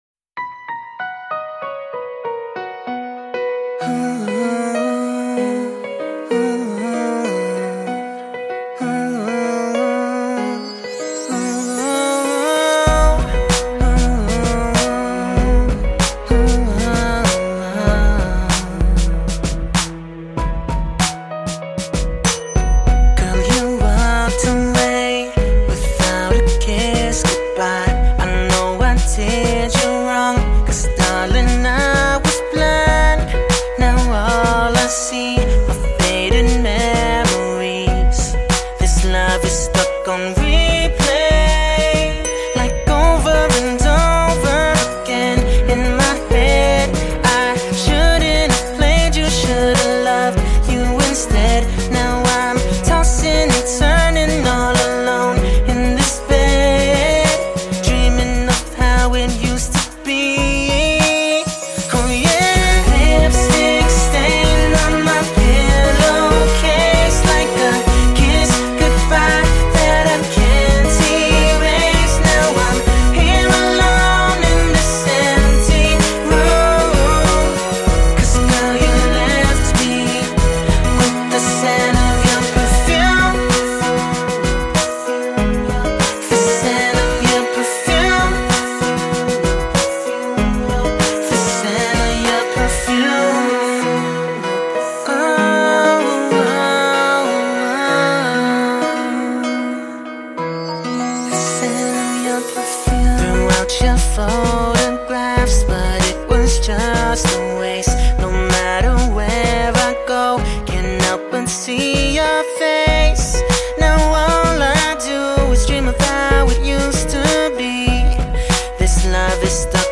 他的声音清澈细腻，颇有女声味道